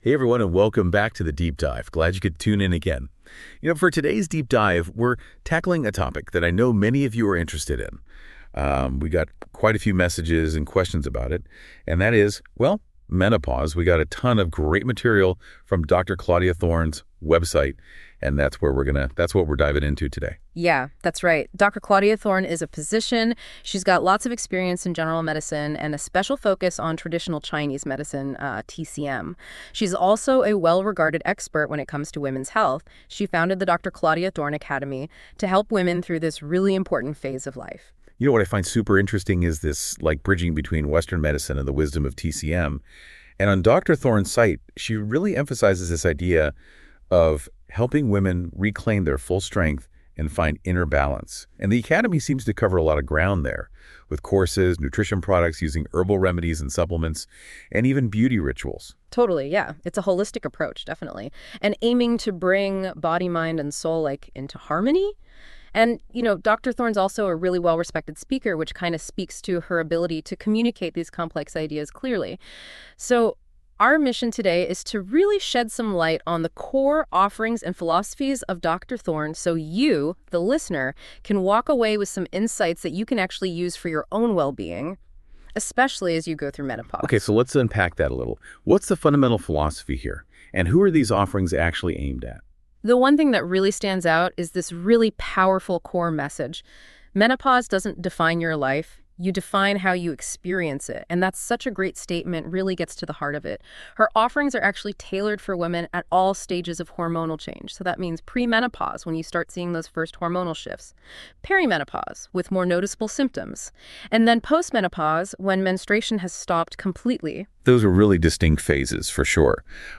Zwei internationale Expert:innen im Gespräch – und meine Expertise